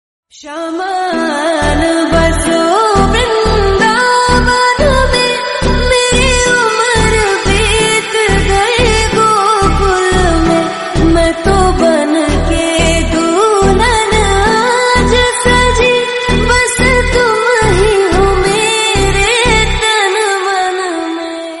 • Soft devotional music
• Spiritual Krishna bhajan vibes
• Short and clear ringtone cut